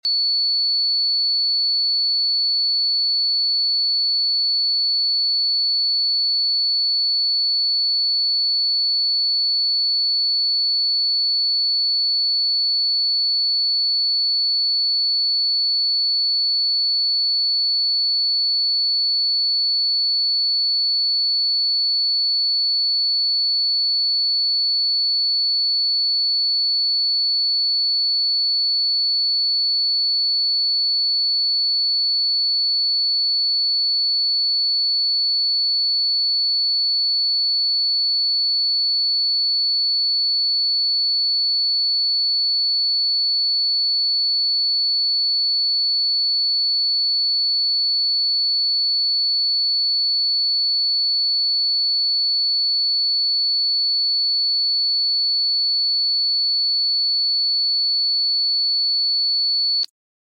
4444 Hz: The Frequency of sound effects free download